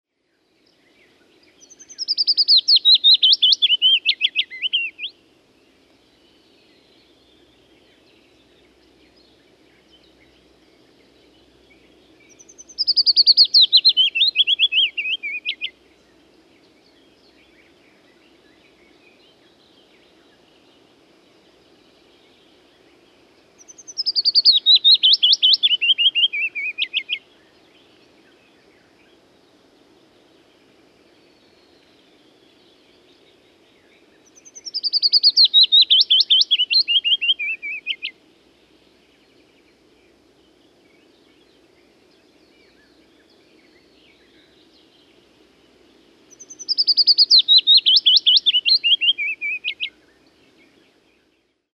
Mikä lintu tässä laulaa?
pajulintu.mp3